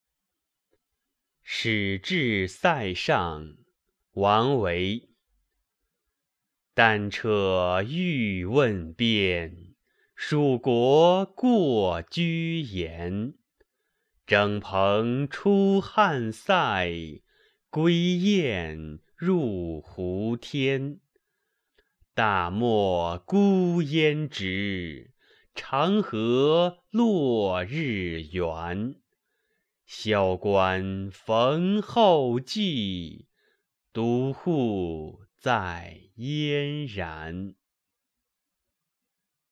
语文教材文言诗文翻译与朗诵 初中语文八年级上册 目录